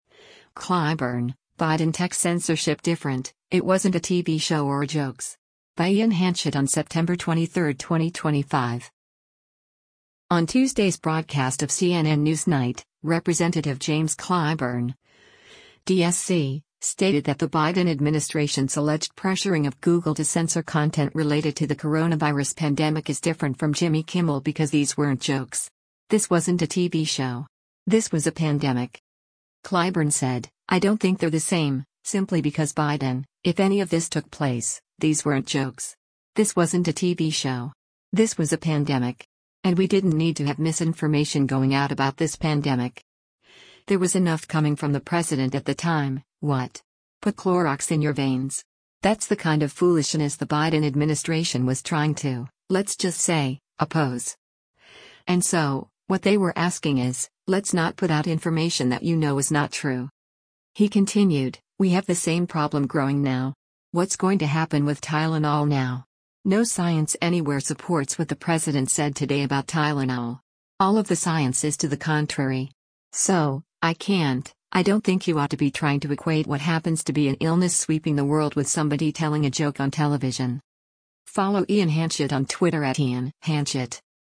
On Tuesday’s broadcast of “CNN NewsNight,” Rep. James Clyburn (D-SC) stated that the Biden administration’s alleged pressuring of Google to censor content related to the coronavirus pandemic is different from Jimmy Kimmel because “these weren’t jokes. This wasn’t a TV show. This was a pandemic.”